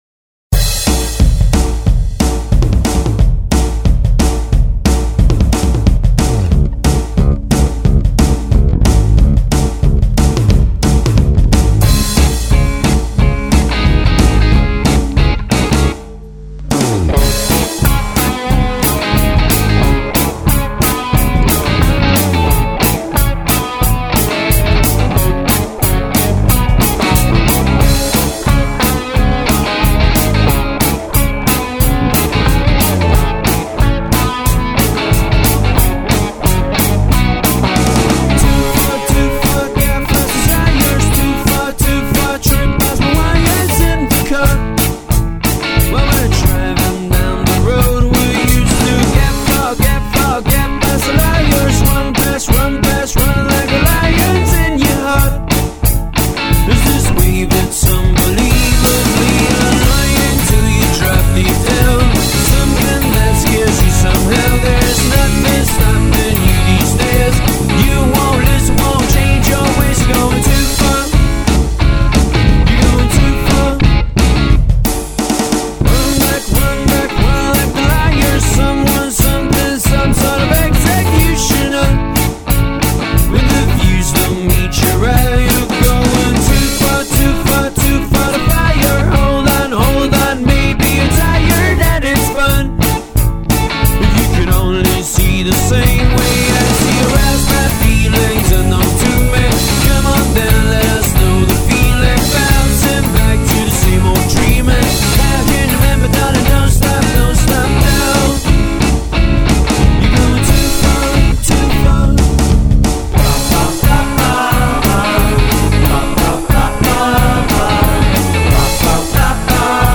are an Indie band from Malta.
bass guitar
drums and backing vocals
catchy and upbeat track